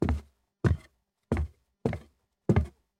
Boots on Wood
Heavy boots walking steadily on a wooden floor with creaks and solid heel strikes
boots-on-wood.mp3